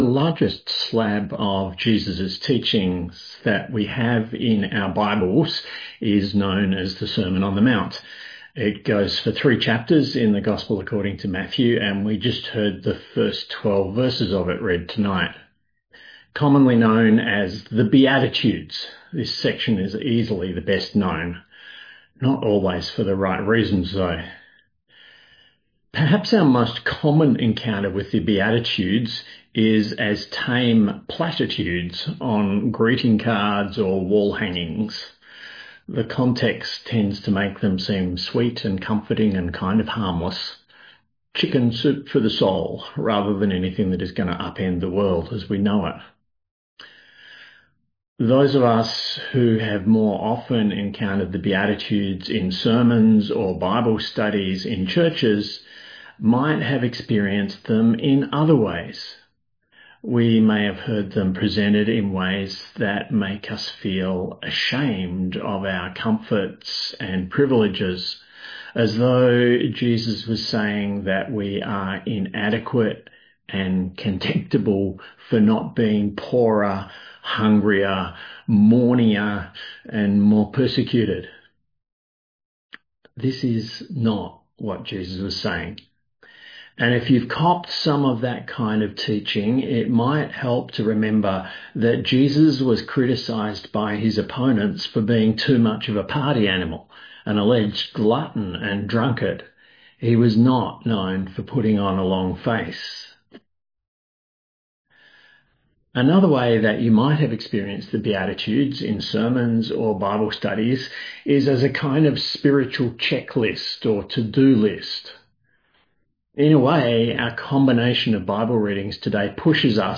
A sermon on Matthew 5:1-12